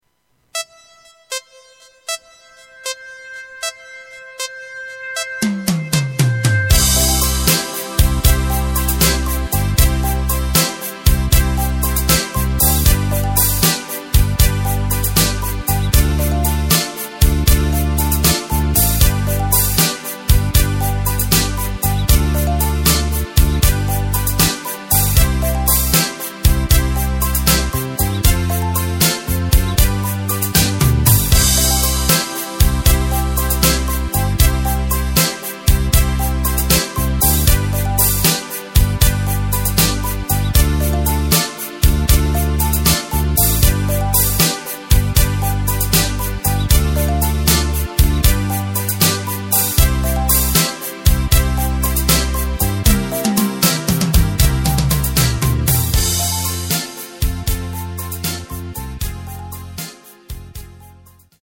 Takt:          4/4
Tempo:         78.00
Tonart:            C
Austropop aus dem Jahr 2016!
Playback mp3 Demo